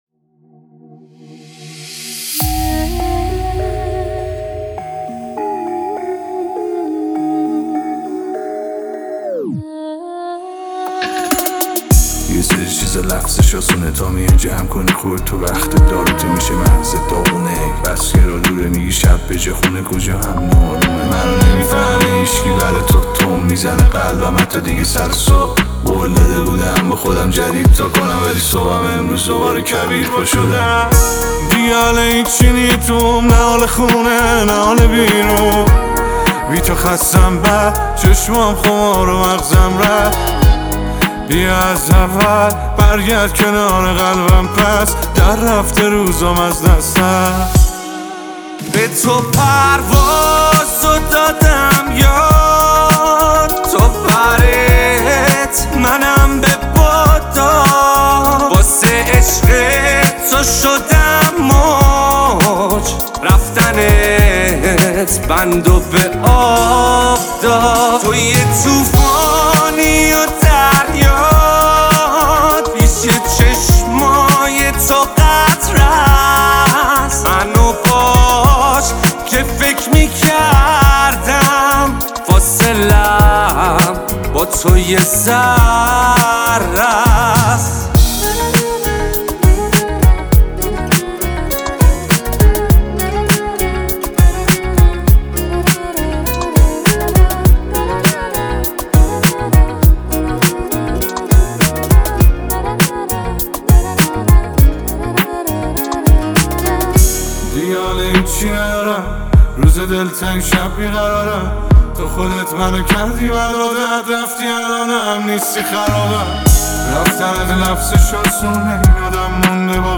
دو خواننده پاپ محبوب مردمی هستند